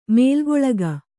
♪ mēlgoḷaga